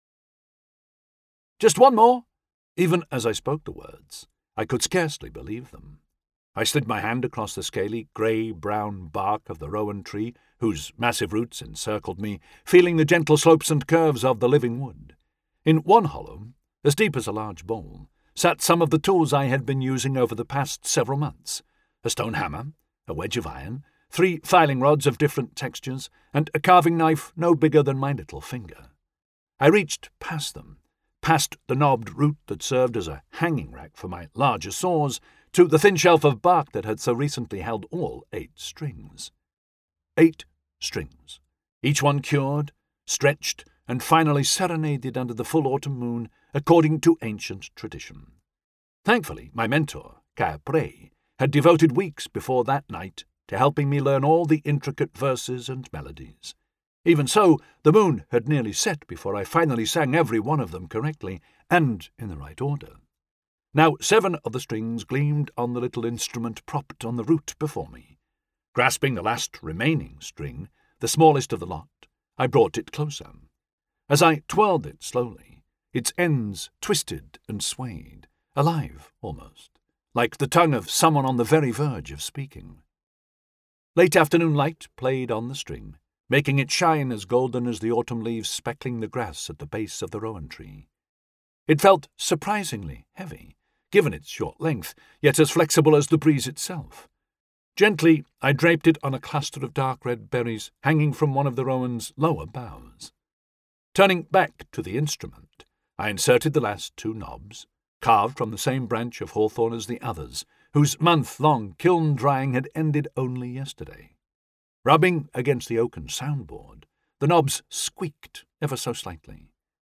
Raging-Fires-Audiobook-Chapter-1-Sample.mp3